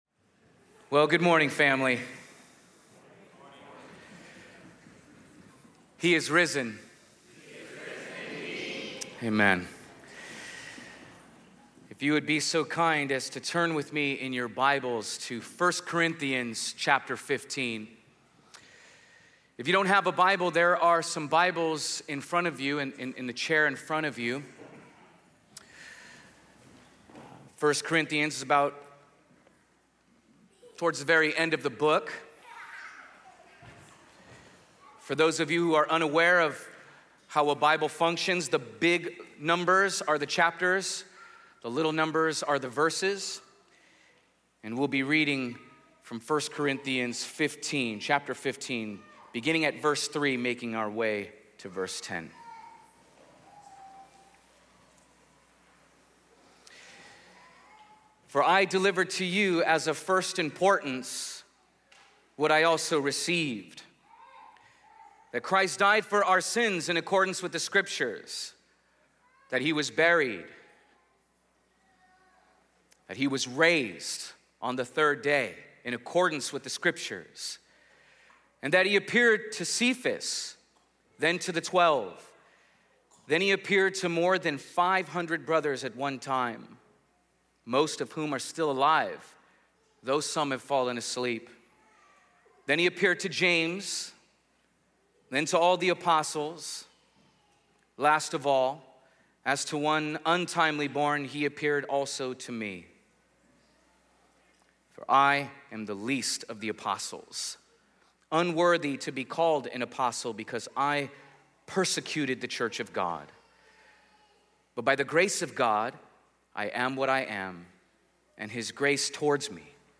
This morning in our Easter Service we gathered to celebrate the resurrection of our Lord Jesus.